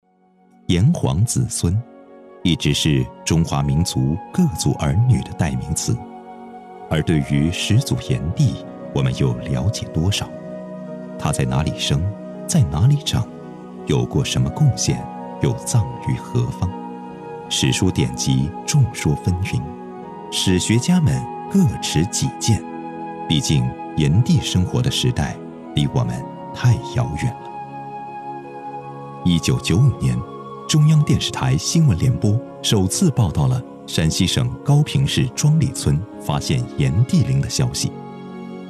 娓娓道来 文化历史
声线可以年轻、大气、洪亮。